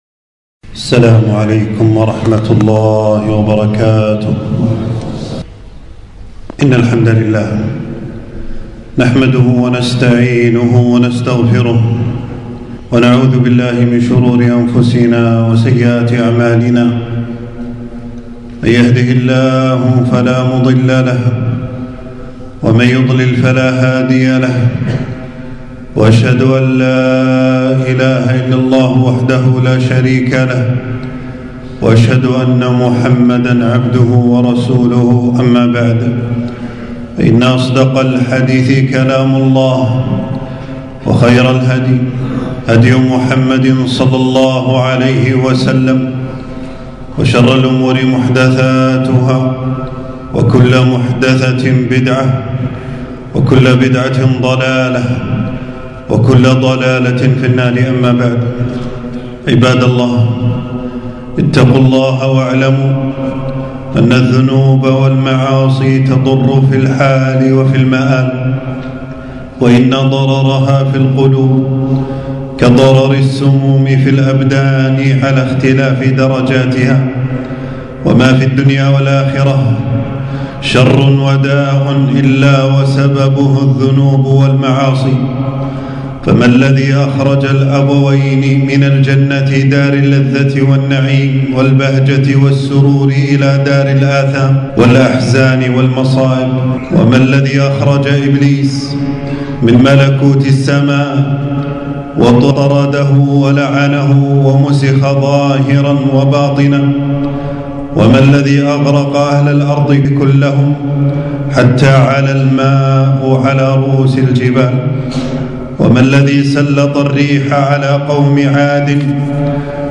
تنزيل تنزيل التفريغ خطبة بعنوان: آثار الذنوب والمعاصي .
في مسجد السعيدي بالجهراء